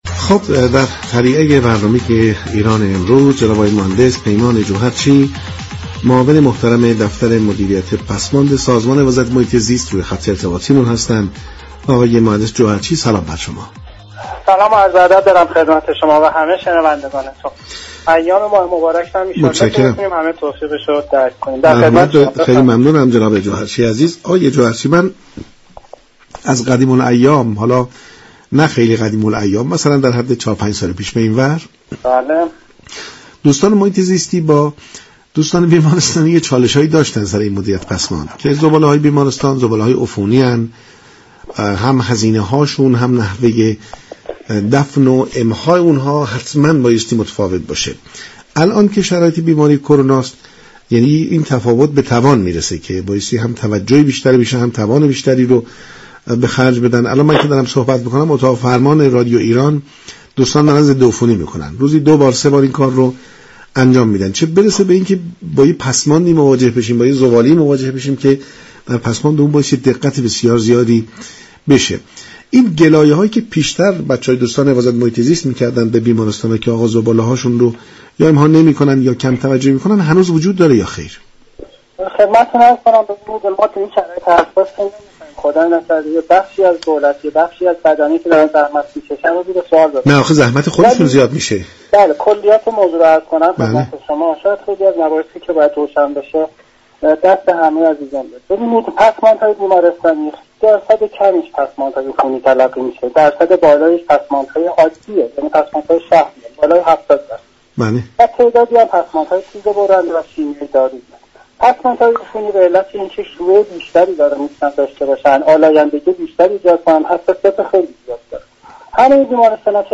معاون مدیریت پسماند سازمان حفاظت محیط زیست در برنامه ایران امروز گفت: در بیشتر بیمارستان های ایران افرادی كه مسئول دستگاه پسماندهای عفونی می شوند درك درستی از كارشان ندارند.